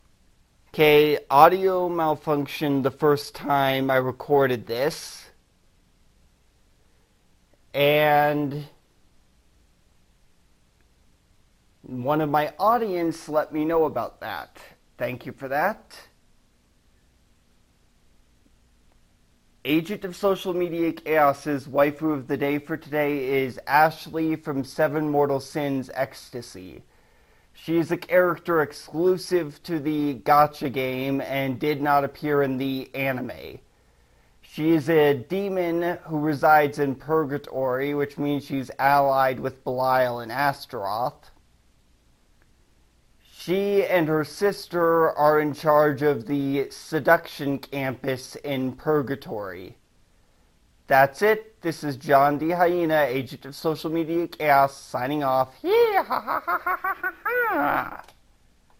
The audio malfunctioned due to my mic not being connected properly the first time I recorded and uploaded this so I deleted the 1st version, rerecorded the video, and reuploaded it